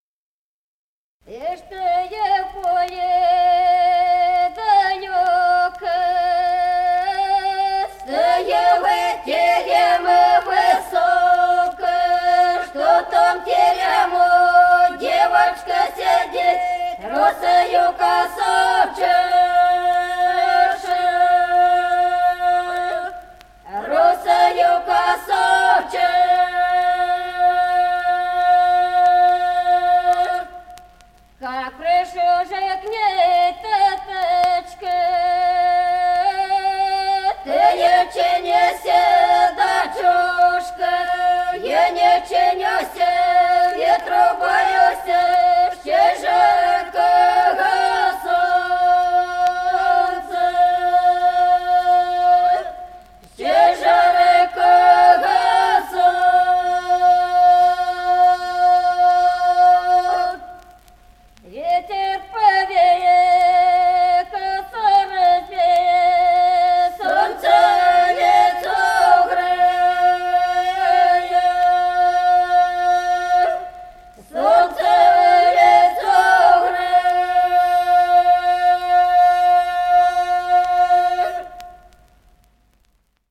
Песни села Остроглядово. И чтой в поле далёко.